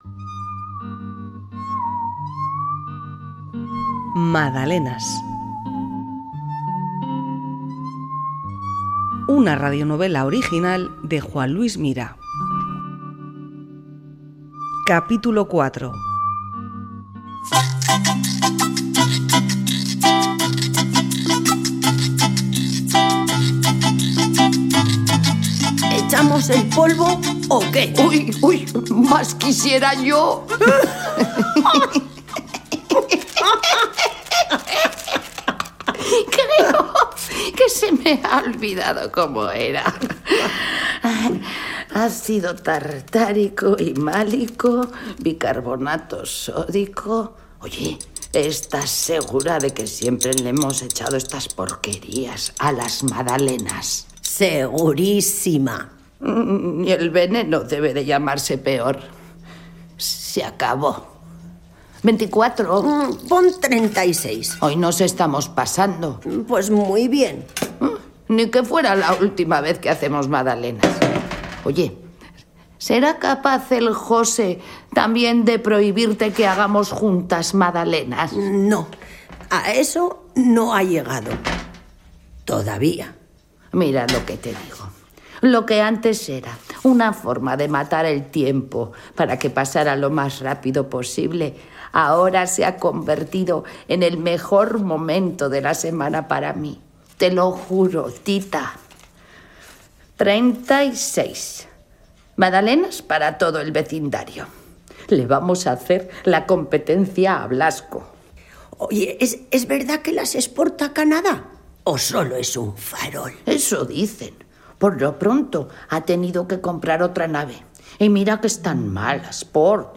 Radionovela Madalenas: Capítulo 4
Grabación y postproducción: Sonora estudios.